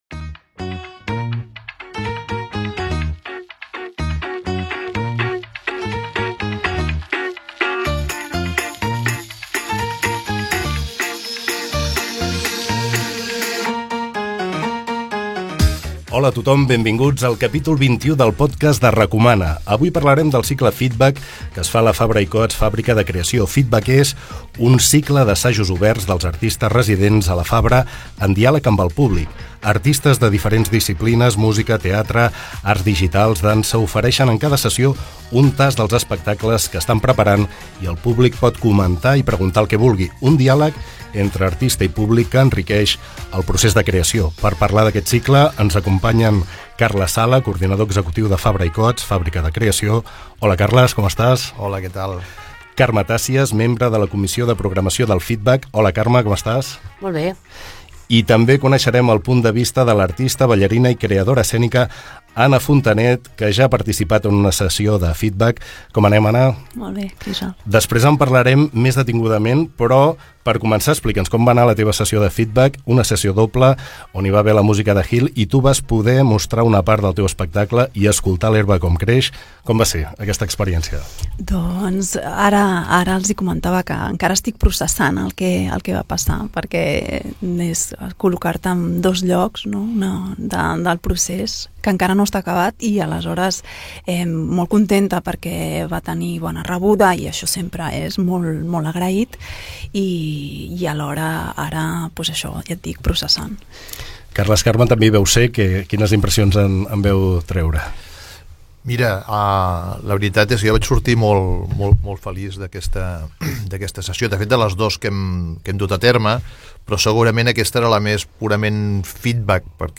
Gènere radiofònic Cultura